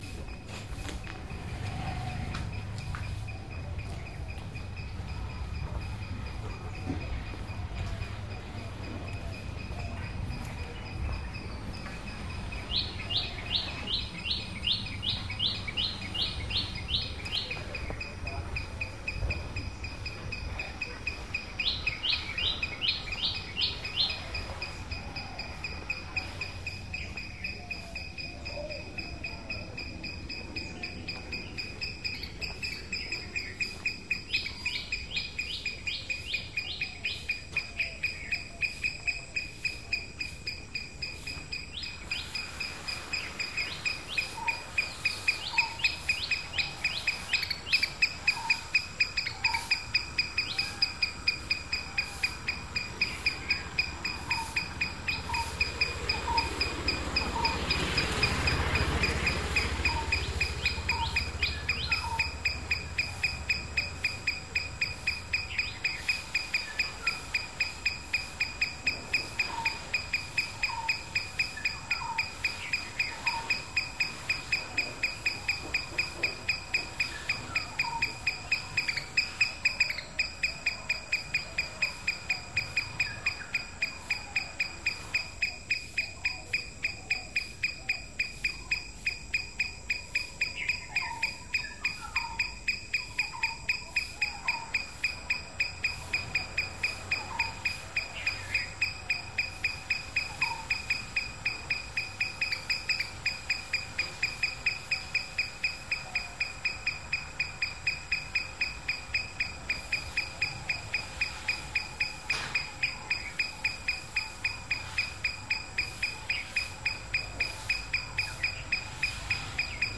Soundwalk in Unawatuna, Sri Lanka - dogs, birdsong, pigeons and sweeps.